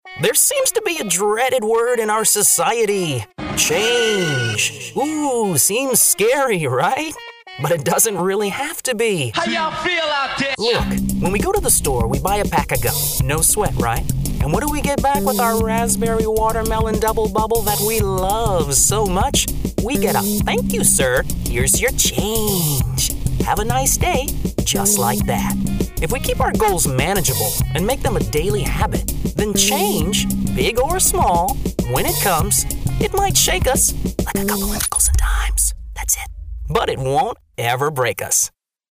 SLightly poetic interpretation. Emotional. NArrative. Perfect Diction. Neutral American Accent.
Sprechprobe: eLearning (Muttersprache):